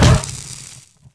rifle_hit_card3.wav